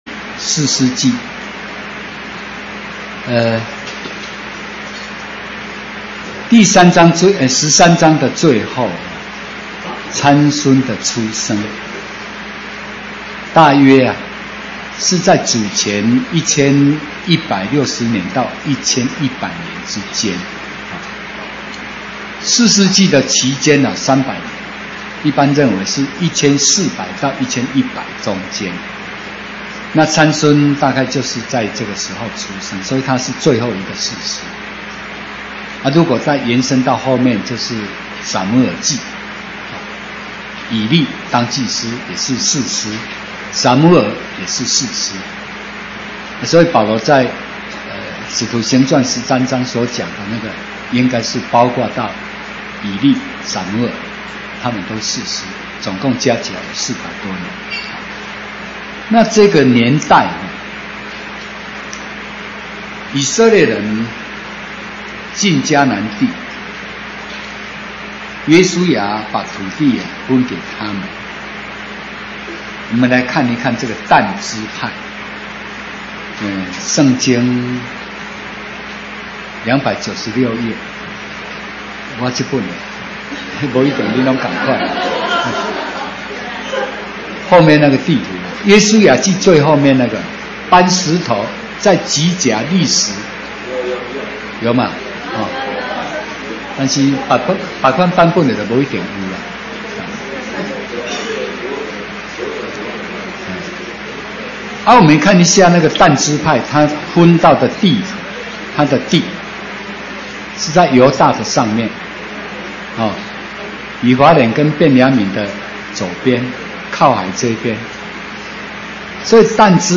講習會
地點 台灣總會 日期 02/14/2016 檔案下載 列印本頁 分享好友 意見反應 Series more » • 士師記 20-1 • 士師記 20-2 • 士師記 20-3 …